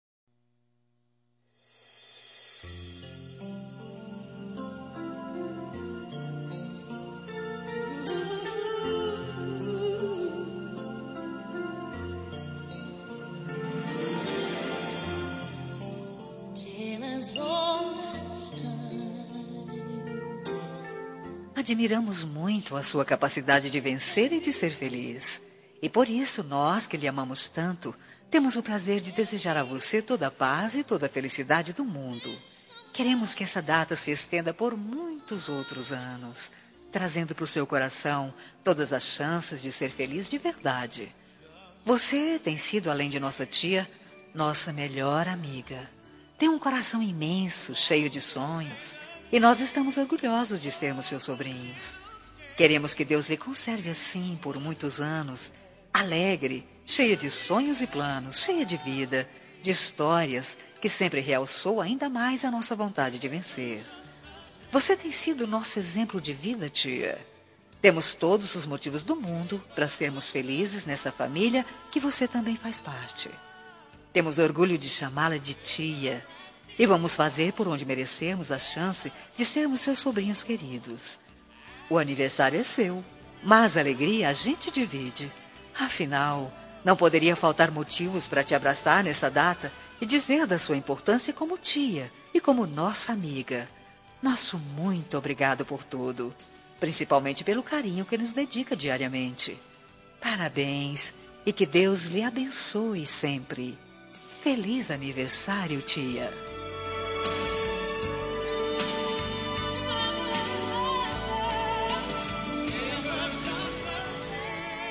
Telemensagem Aniversário de Tia – Voz Feminina – Cód: 1995